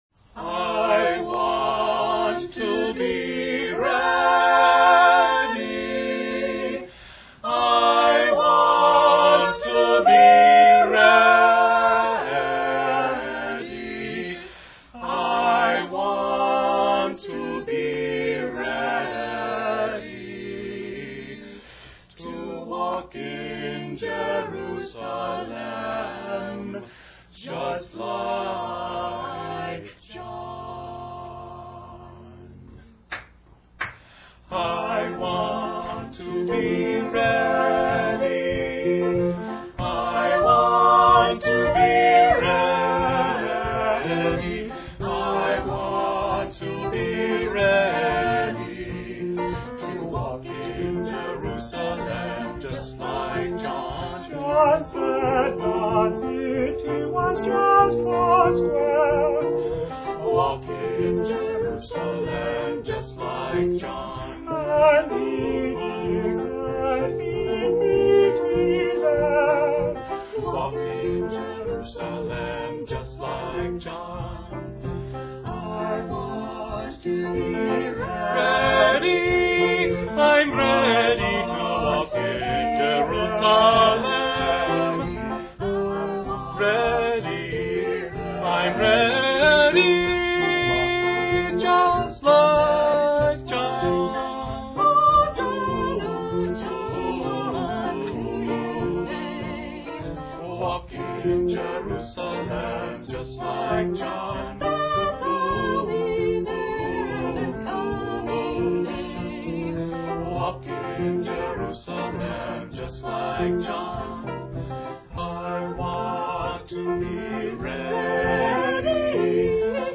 African-American Spiritual